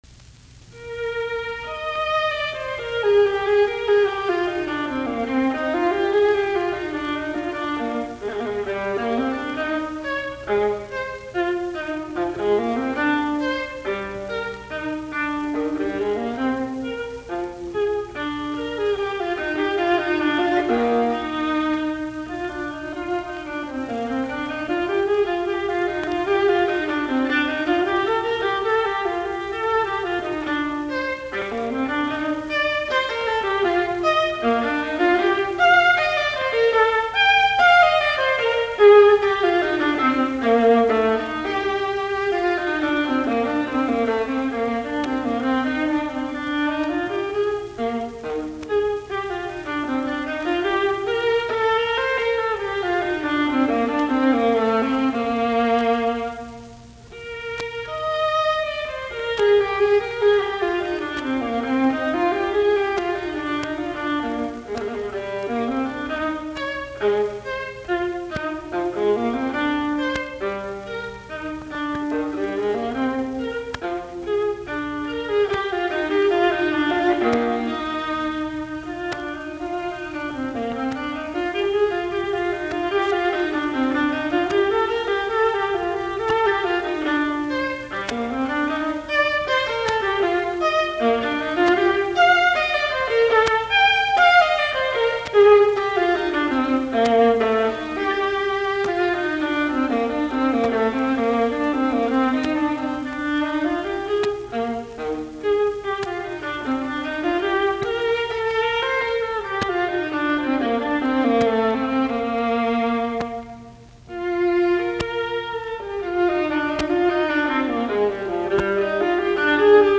alttoviulu